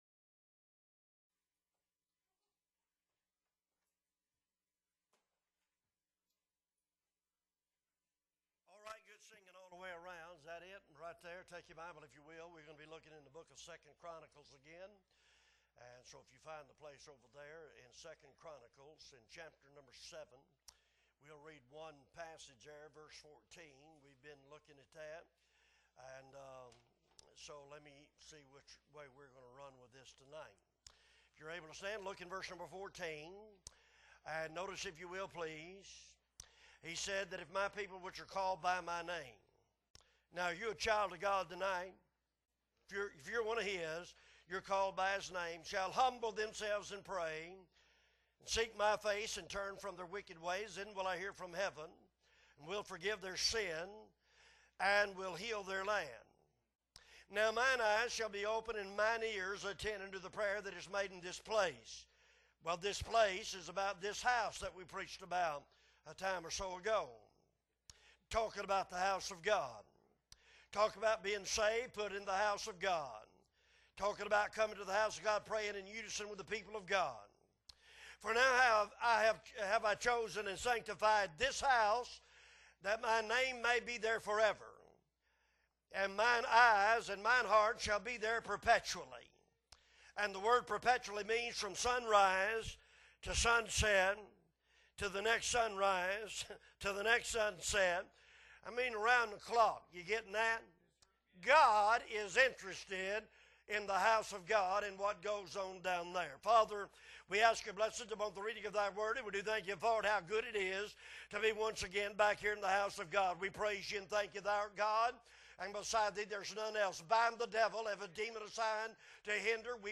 September 21, 2022 Wednesday Night Service - Appleby Baptist Church